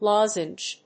音節loz・enge 発音記号・読み方
/lάzndʒ(米国英語), lˈɔzndʒ(英国英語)/